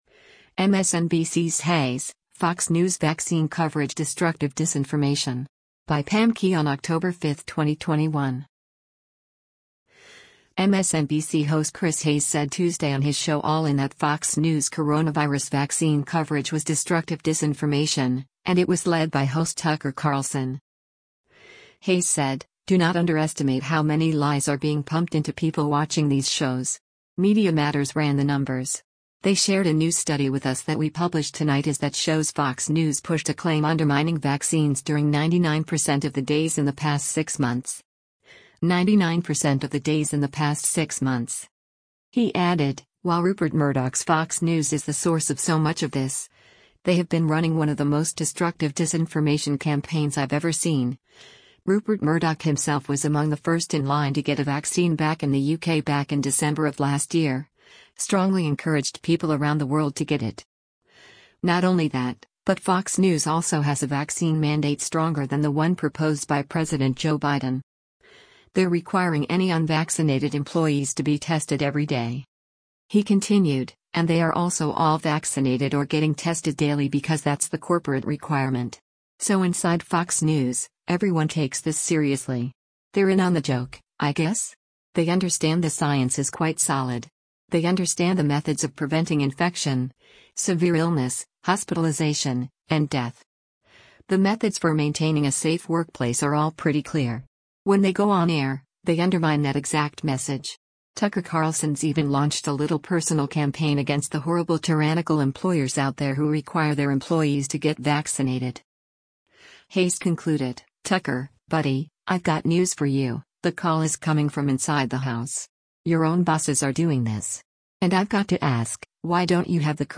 MSNBC host Chris Hayes said Tuesday on his show “All In” that Fox News’ coronavirus vaccine coverage was “destructive disinformation,” and it was led by host Tucker Carlson.